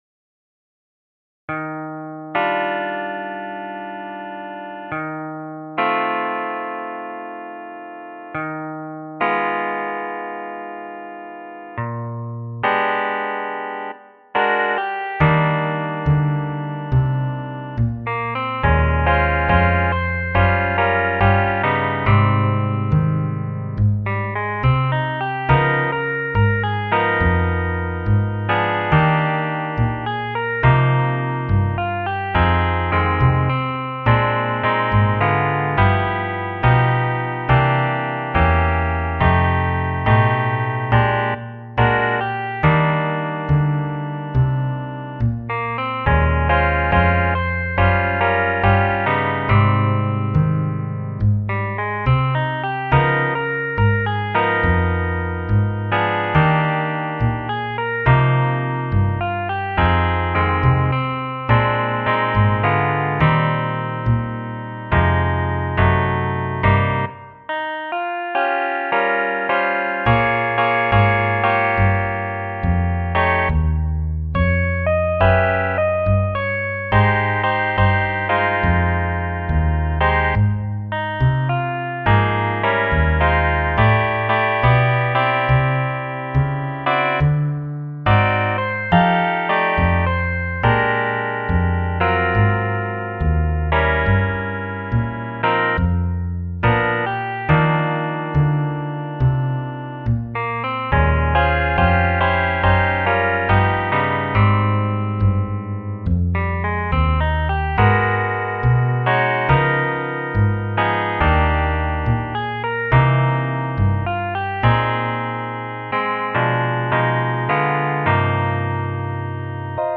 Ноты для гитары.